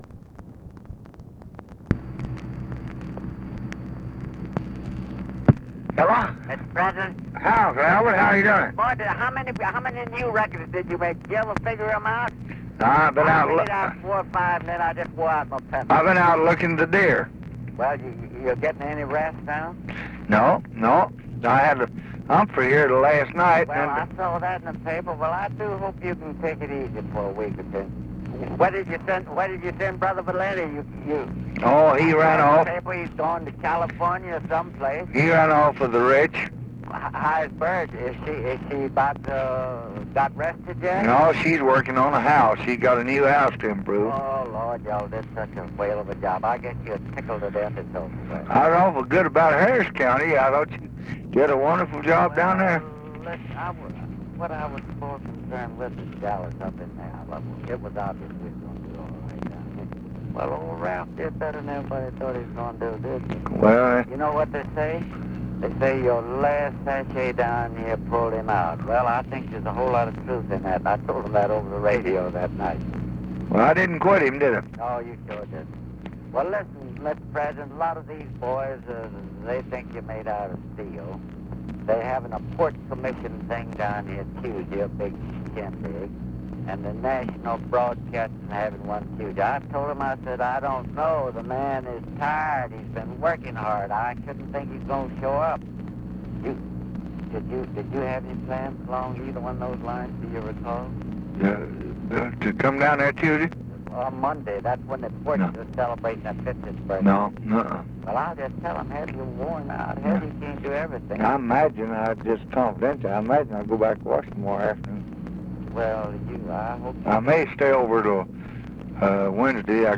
Conversation with ALBERT THOMAS, November 7, 1964
Secret White House Tapes